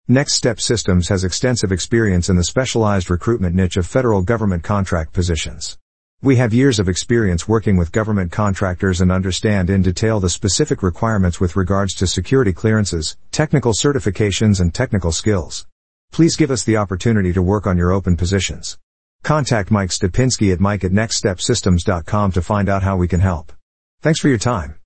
Security Clearance Employers, Listen to Our Artificial Intelligence (AI) on How Our IT Staffing Company Can Help You with Government and Federal Information Technology Openings